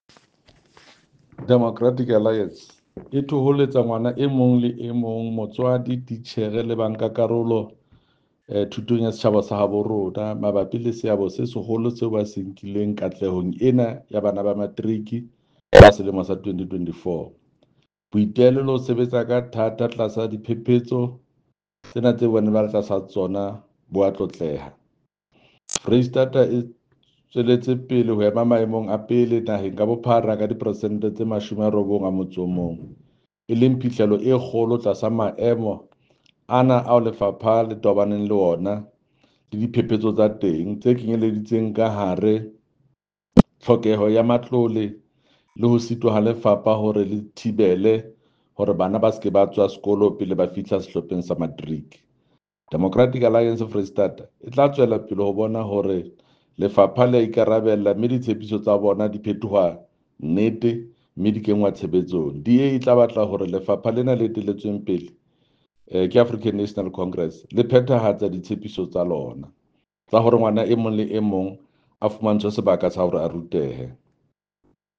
Sesotho soundbite by Jafta Mokoena MPL